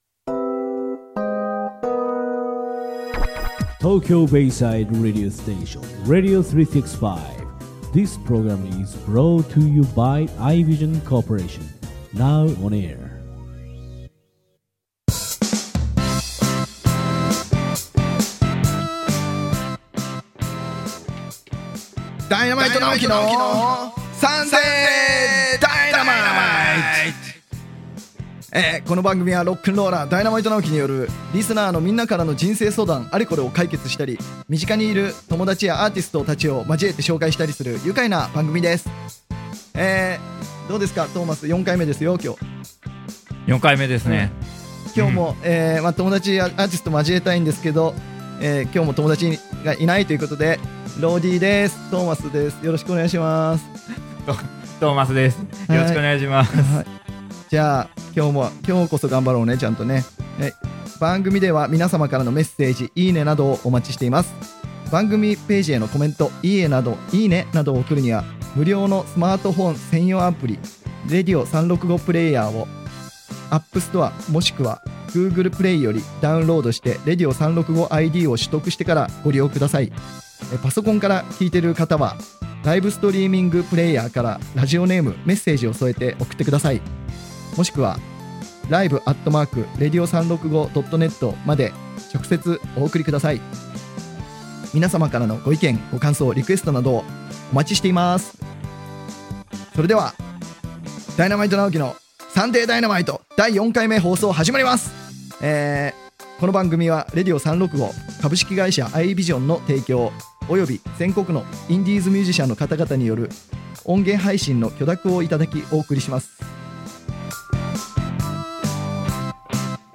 【この音源は生放送のアーカイブ音源となります。】